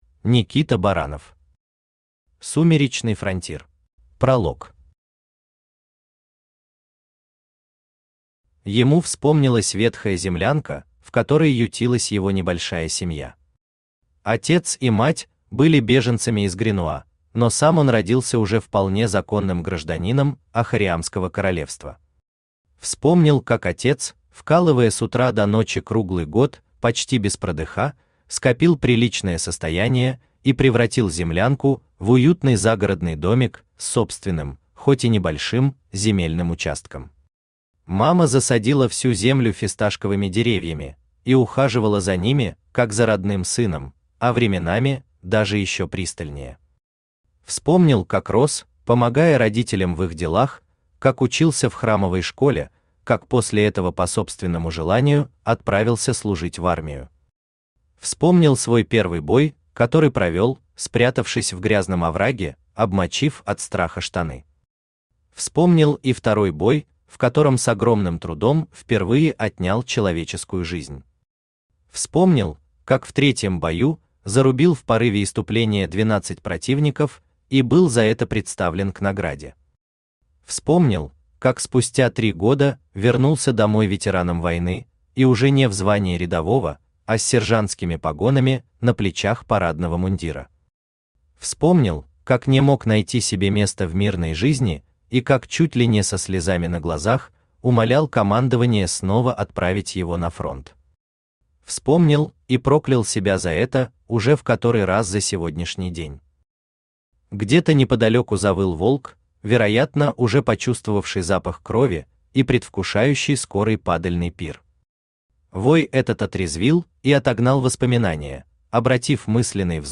Aудиокнига Сумеречный Фронтир Автор Никита Баранов Читает аудиокнигу Авточтец ЛитРес.